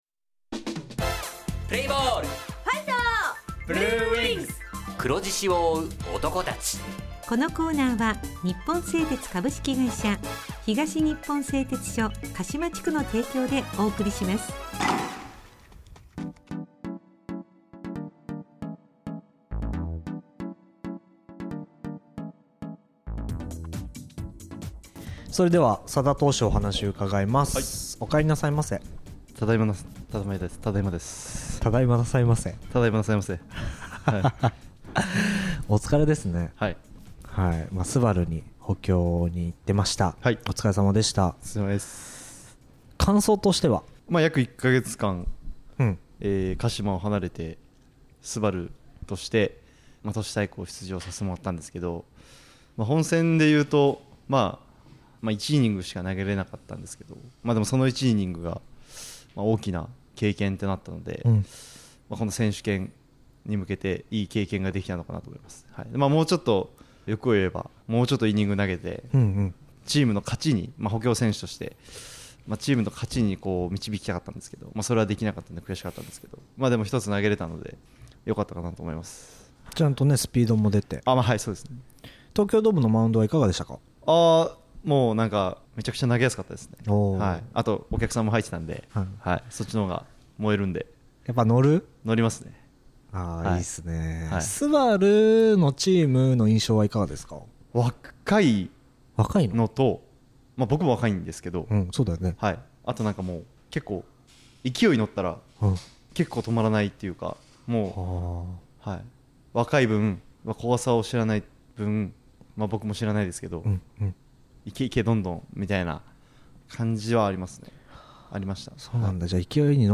地元ＦＭ放送局「エフエムかしま」にて鹿島硬式野球部の番組放送しています。